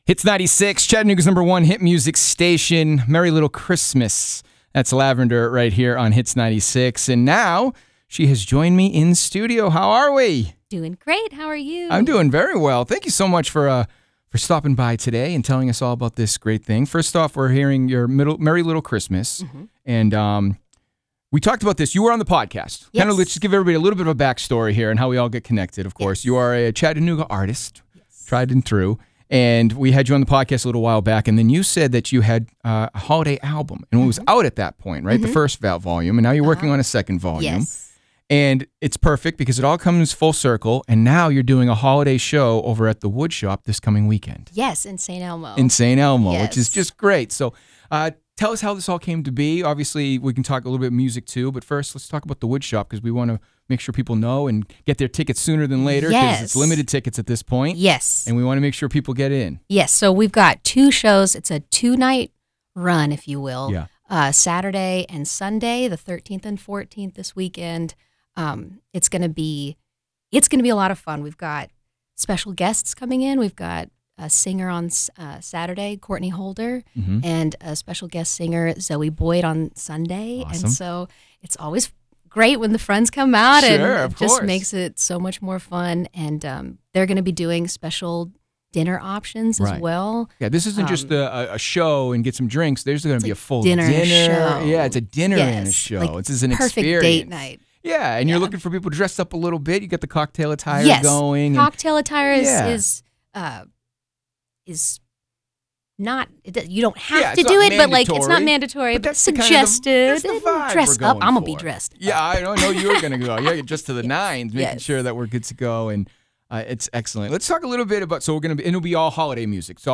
xmas-interview.wav